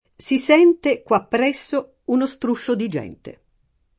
Si S$nte, kUa ppr$SSo, uno Str2ššo di J$nte] (Pascoli)